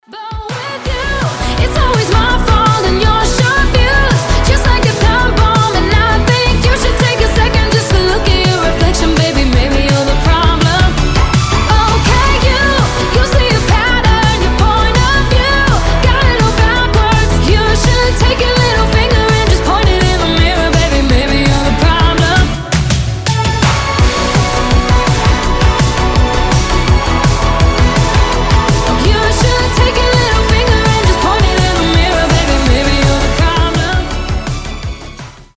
• Качество: 320, Stereo
диско
Synth Pop
заводные
Dance Pop